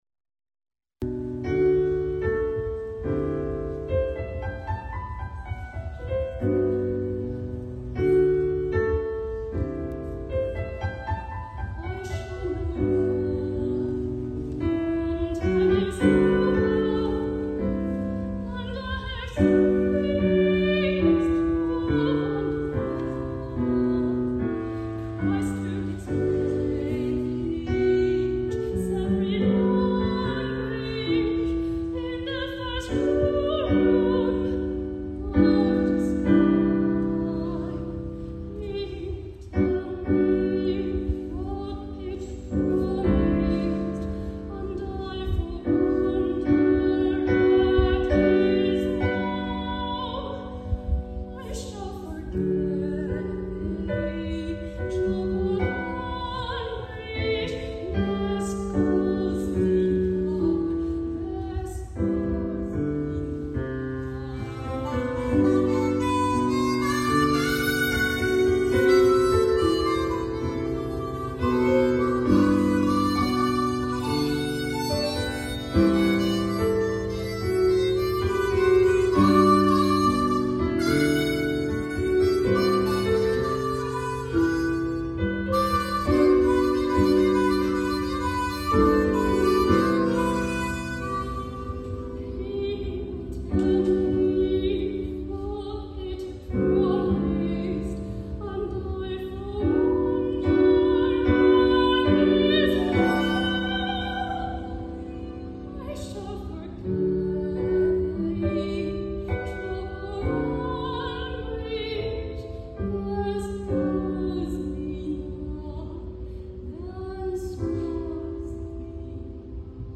soprano
piano
harmonica
Fudan Art Museum, Shanghai, China, 2025